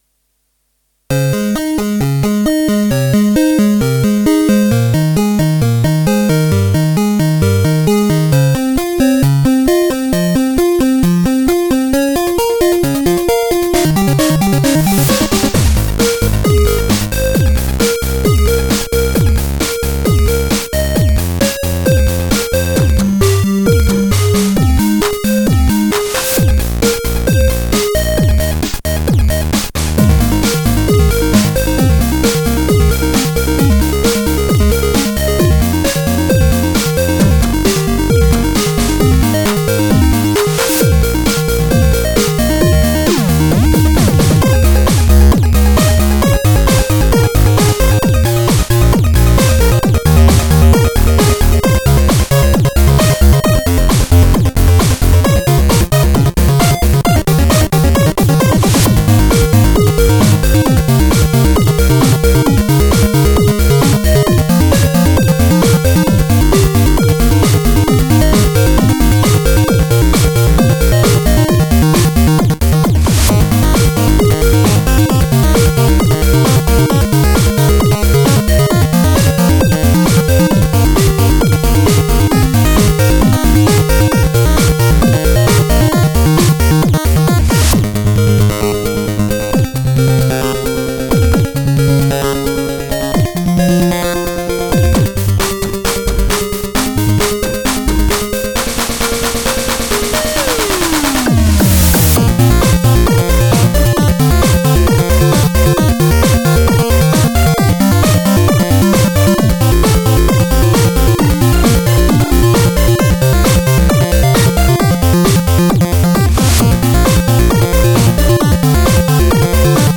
la sigla conclusiva della prima stagione
in versione extended.